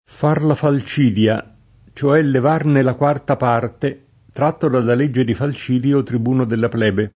f#r la fal©&dLa, ©o$ llev#rne la kU#rta p#rte, tr#tto dalla l%JJe di fal©&dLo trib2no della pl$be] (Varchi) — err. un sing. falcidie — nel sign. giur., anche agg. f. in legge falcidia e in quarta falcidia